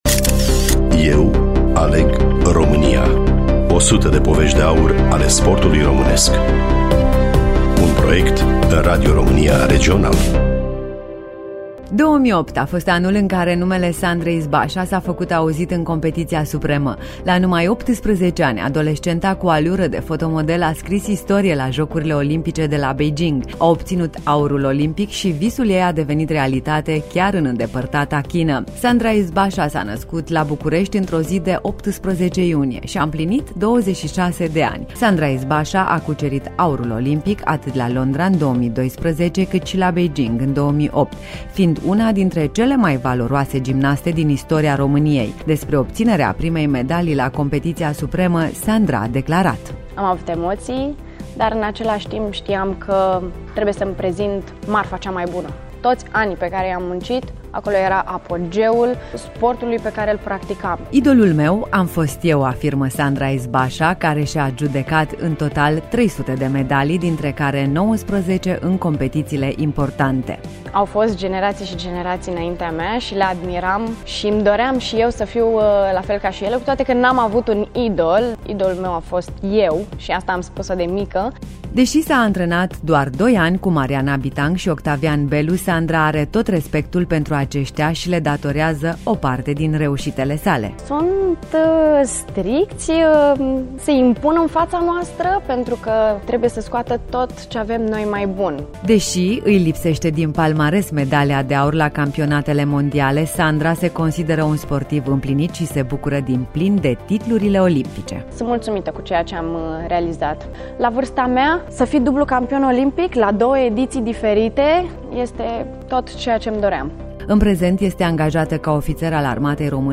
Studioul Radio România Reşiţa